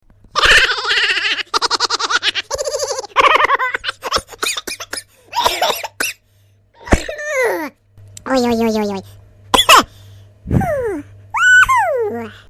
Play Cartoon Funny Laugh - SoundBoardGuy
Play, download and share Cartoon Funny Laugh original sound button!!!!
cartoon-funny-laugh-sound-effects-no-copyright.mp3